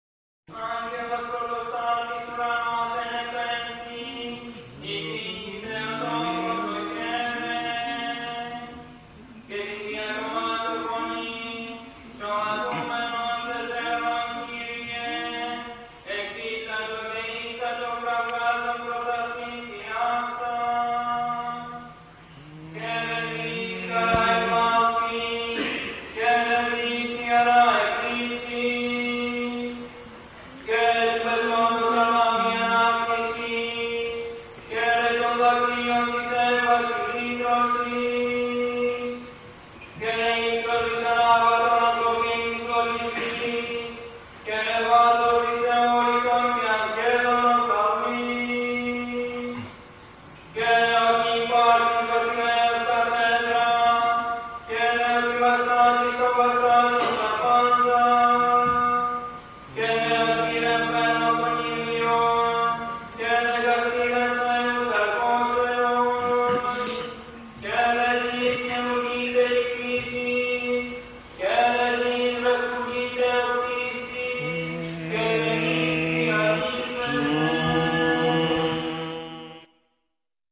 Εκφώνησις υπό του Σεβ. Μητρ. Φιλαδελφείας κ. Μελίτωνος.
Φανάρι - Μάρτιος 1991